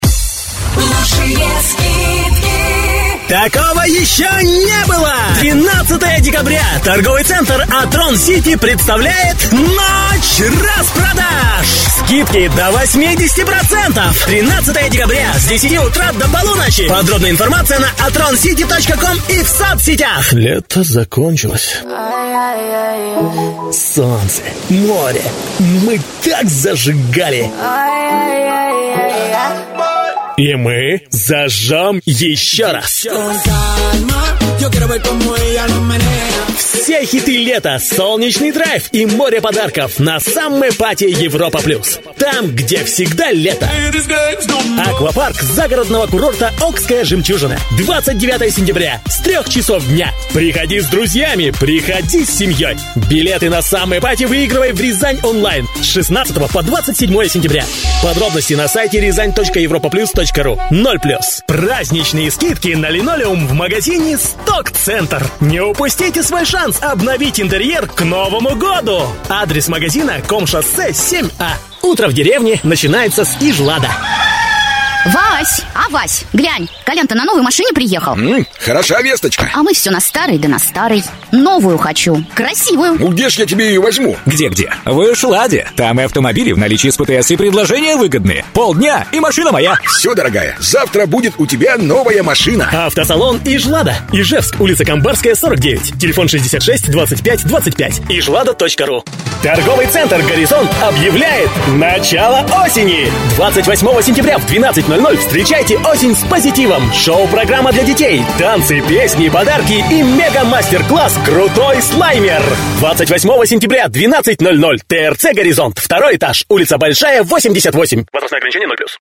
Молодой энергичный голос.
Тракт: микрофон RODE NTK, предусилитель dbx-376, карта TC Impact Twin(FireWire), Акустическая кабина(Mappysil пирамидки)